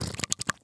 household
Gel Bottle Squeeze